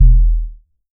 DUB BASS 1.wav